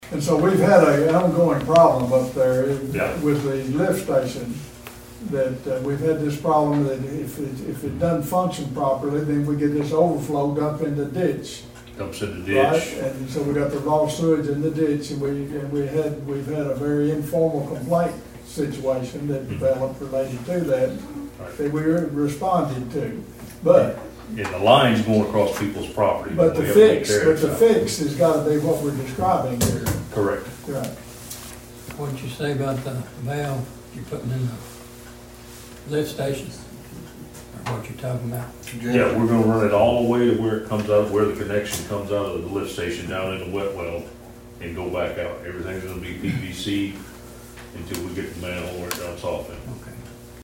At Sharon’s  City Board meeting, dilapidated sewer lines were brought to the board’s attention.
The board inquired more about the issue….